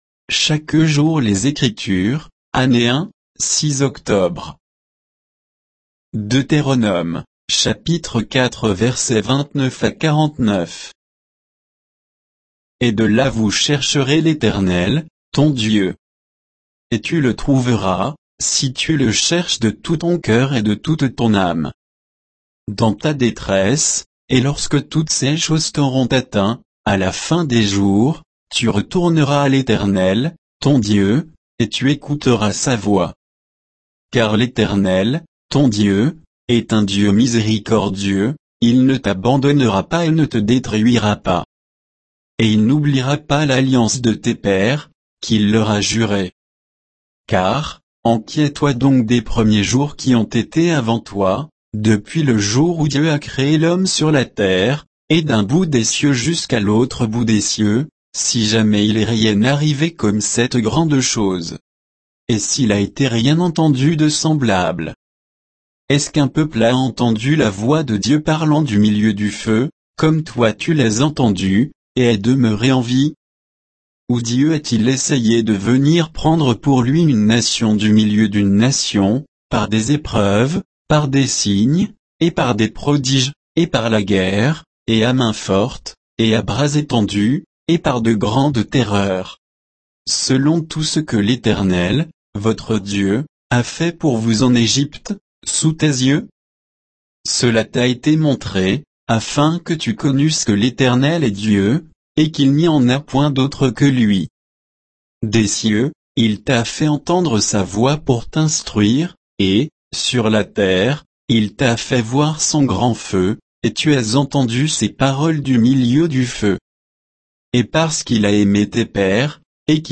Méditation quoditienne de Chaque jour les Écritures sur Deutéronome 4, 29 à 49,